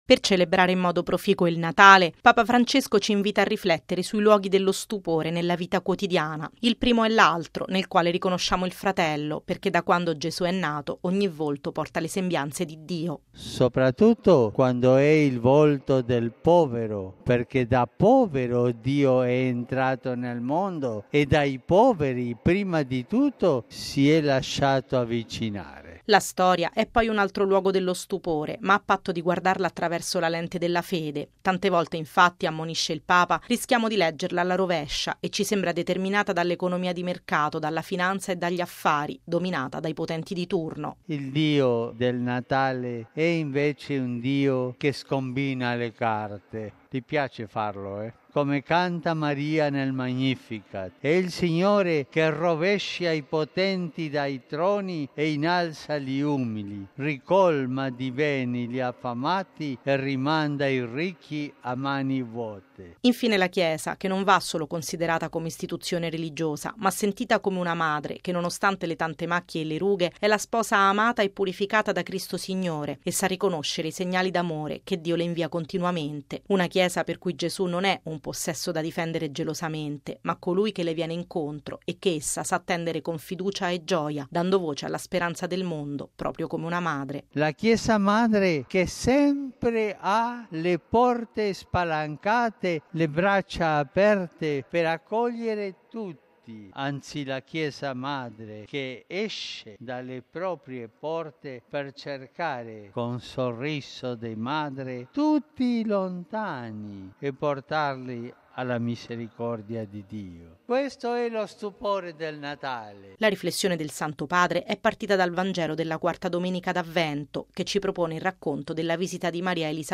Così Papa Francesco all’Angelus in Piazza San Pietro, in cui si è soffermato sui “luoghi” dello stupore: l’altro, la storia e la Chiesa. Il servizio